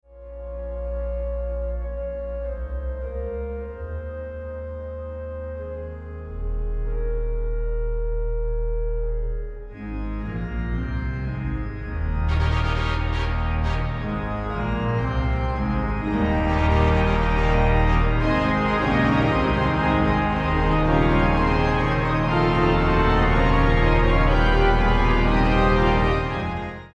recorded  on the Christchurch Town Hall